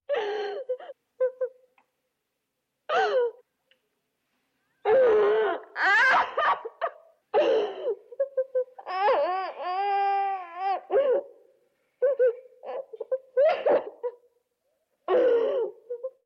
Звуки женского плача
Женщина рыдает в горьких слезах